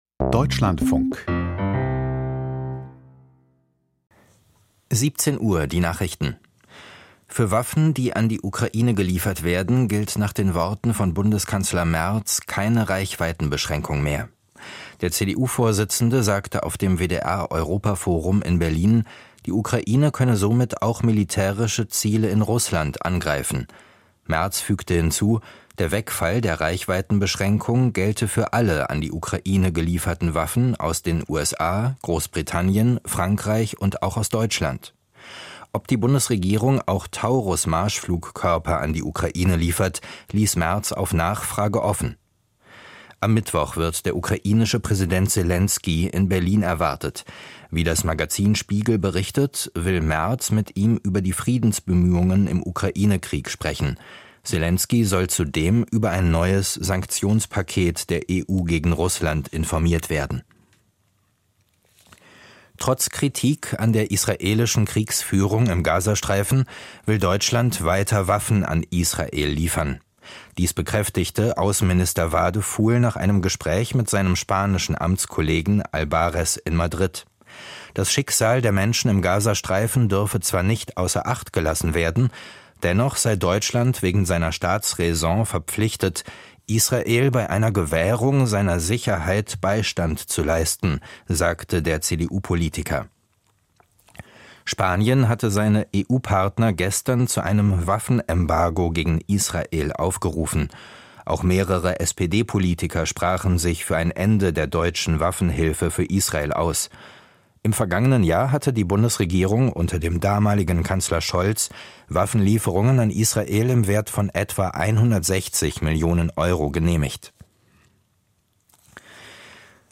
Die Nachrichten vom 26.05.2025, 17:00 Uhr
Aus der Deutschlandfunk-Nachrichtenredaktion.